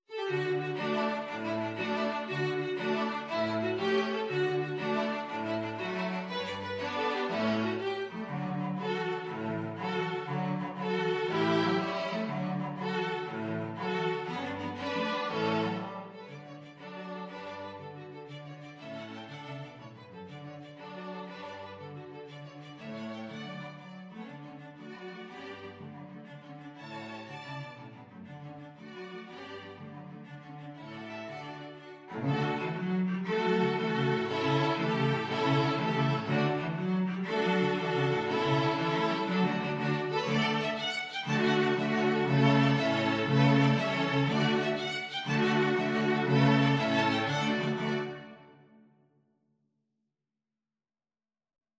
Violin 1Violin 2Cello 1Cello 2
6/8 (View more 6/8 Music)